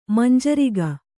♪ manjariga